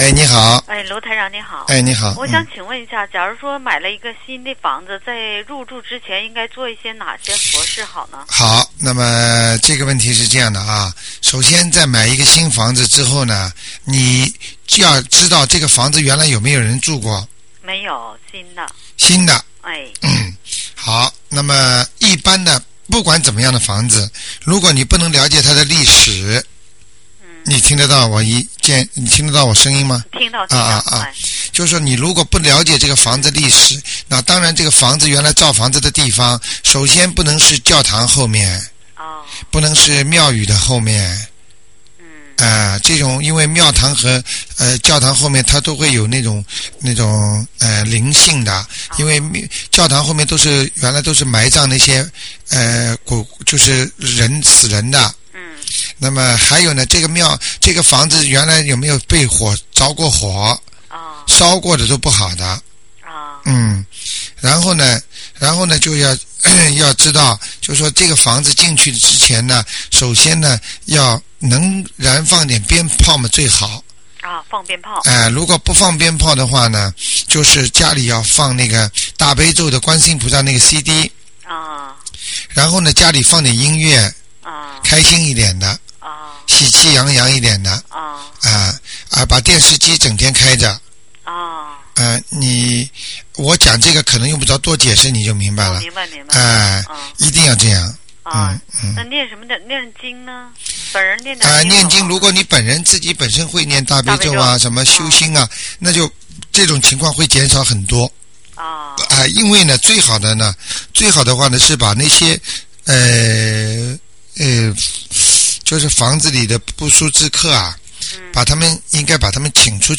目录：☞ 2008年02月_剪辑电台节目录音集锦